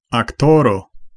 Ääntäminen
Synonyymit comédien tragédien Ääntäminen France Tuntematon aksentti: IPA: /ak.tœʁ/ Haettu sana löytyi näillä lähdekielillä: ranska Käännös Konteksti Ääninäyte Substantiivit 1. aktoro teatteri Suku: m .